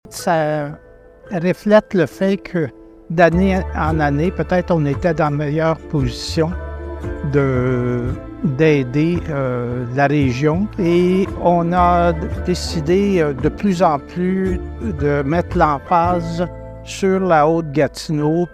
L’extrait suivant provient d’une vidéo promotionnelle :